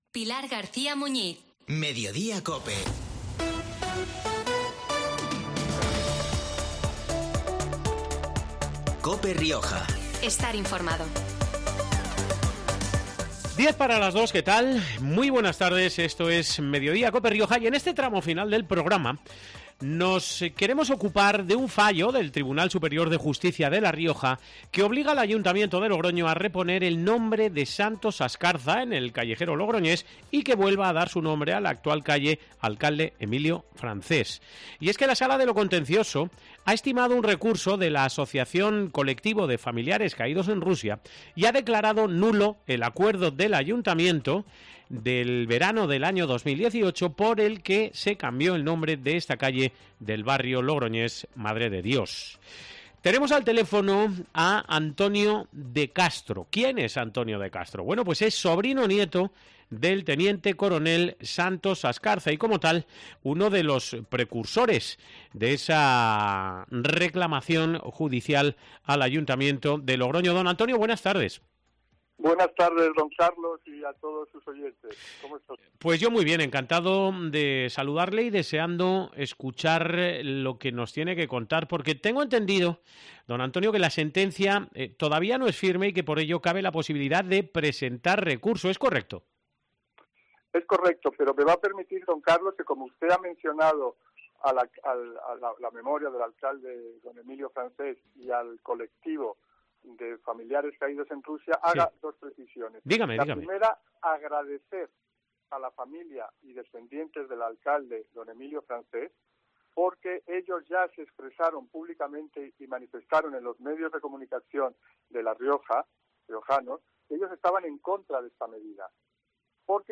Y este mediodía nos ha atendido por teléfono para ofrecer su versión de los hechos.